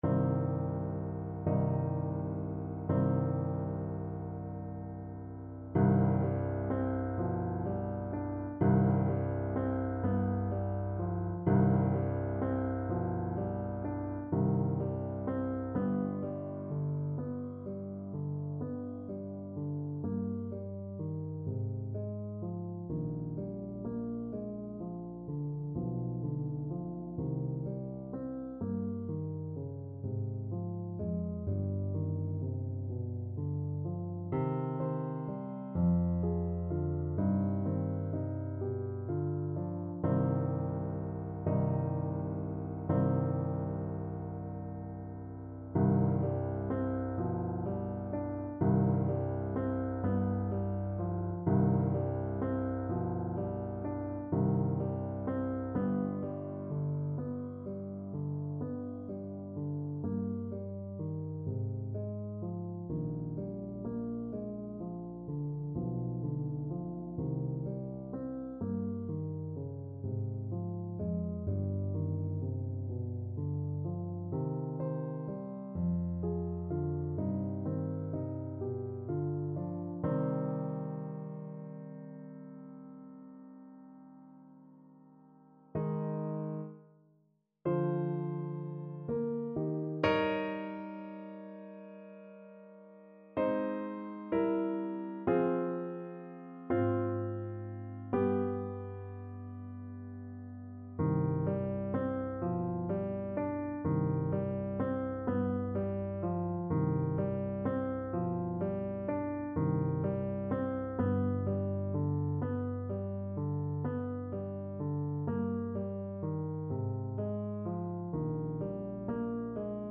Play (or use space bar on your keyboard) Pause Music Playalong - Piano Accompaniment Playalong Band Accompaniment not yet available transpose reset tempo print settings full screen
C minor (Sounding Pitch) (View more C minor Music for Trombone )
6/8 (View more 6/8 Music)
= 42 Andante con moto (View more music marked Andante con moto)